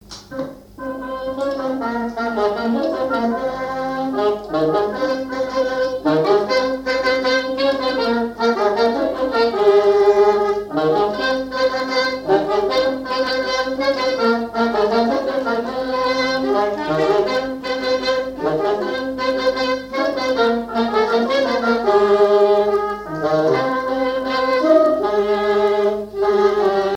trompette
saxophone
Basse
clarinette
circonstance : fiançaille, noce
Pièce musicale inédite